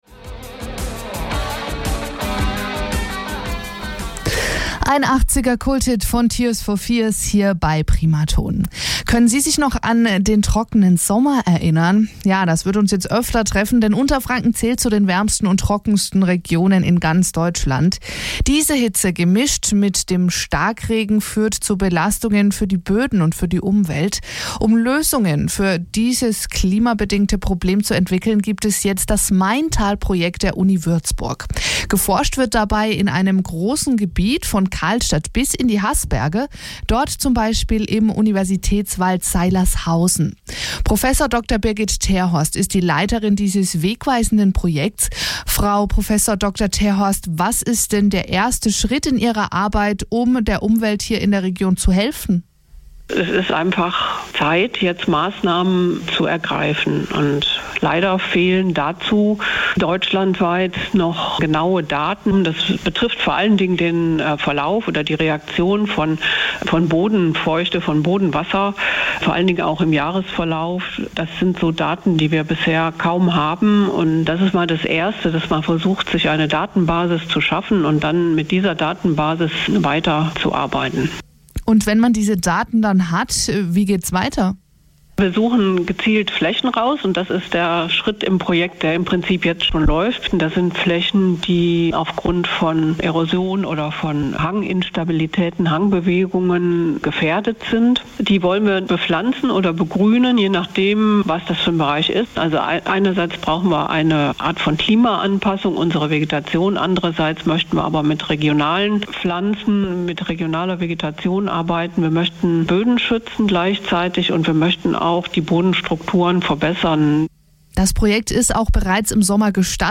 Maintal-Projekt - Mitschnitt Interview bei Radio Primaton